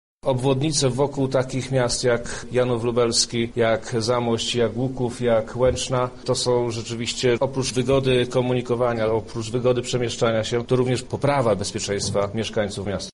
Inwestycje będą realizowane sukcesywnie w najbliższym dziesięcioleciu – mówi Przemysław Czarnek, poseł Prawa i Sprawiedliwości